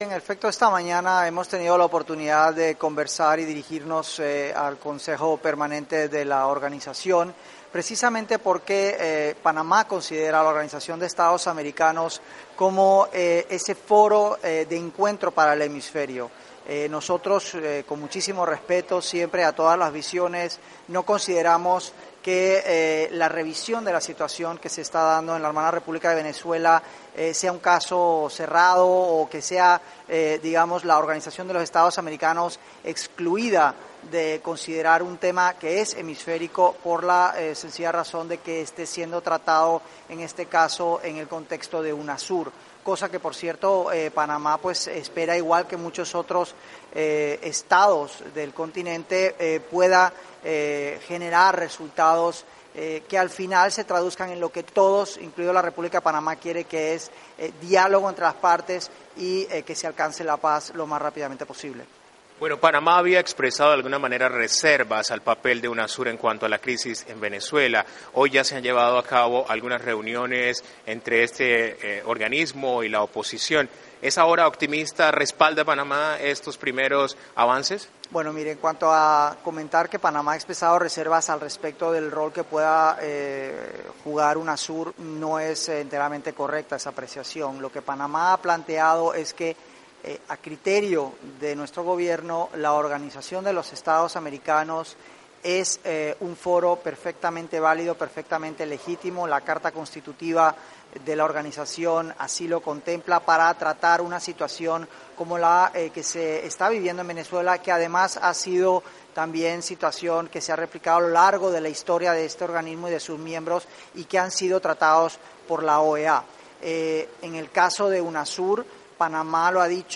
ENTREVISTA CANCILLER DE PANAMA